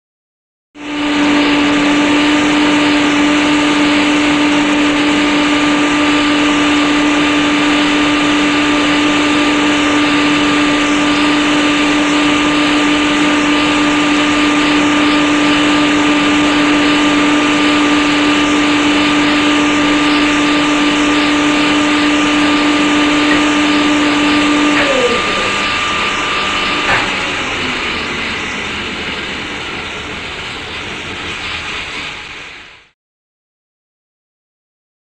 Generator; Large; Large Generator Constant, Then Switch Off.